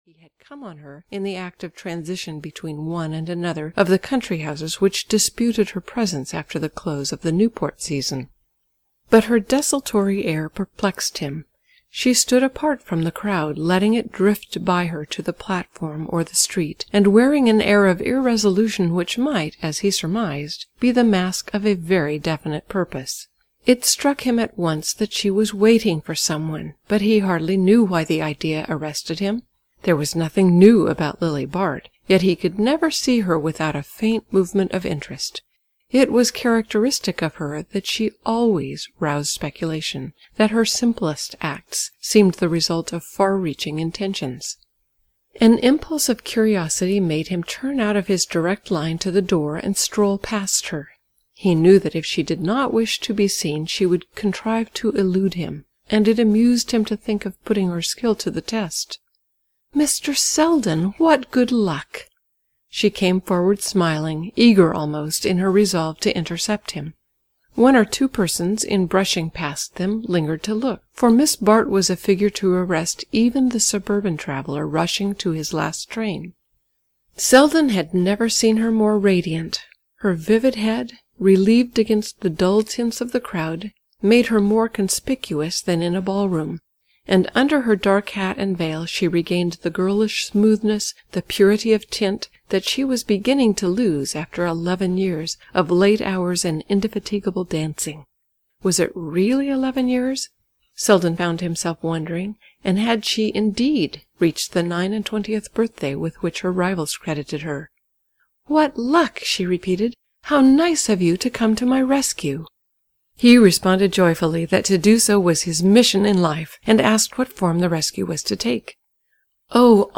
The House of Mirth (EN) audiokniha
Ukázka z knihy